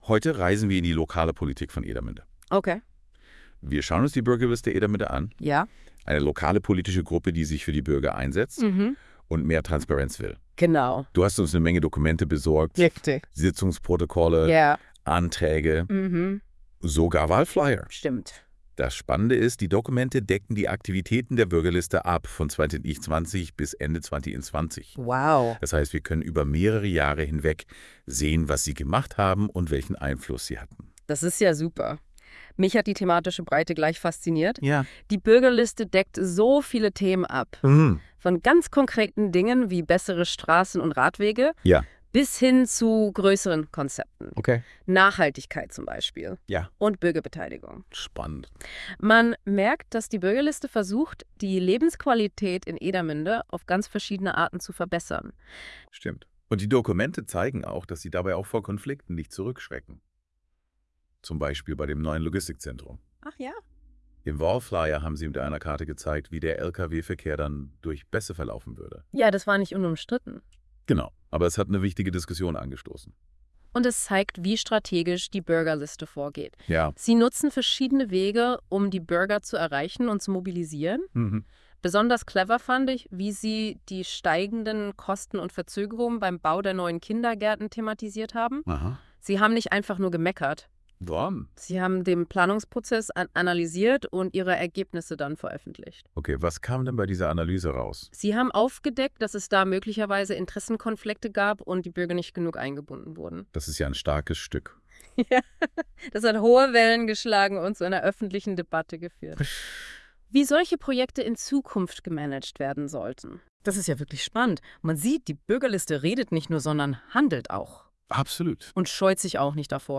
Details Kategorie: Podcast Podcast über die Aktivitäten der Bürgerliste Edermünde Hinweis: Dieser Podcast wurde mit Hilfe einer künstlichen Intelligenz erzeugt.